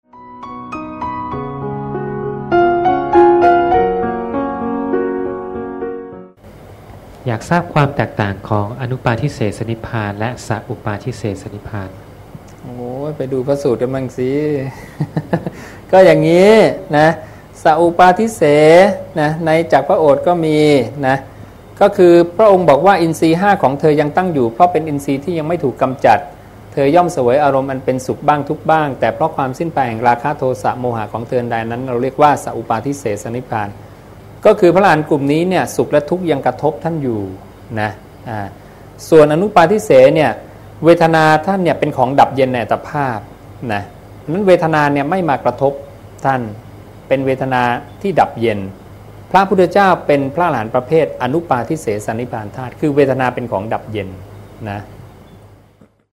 สนทนาธรรมค่ำเสาร์ 23 ก.ค. 54
วัดนาป่าพง ลำลูกกา คลอง ๑๐ ปทุมธานี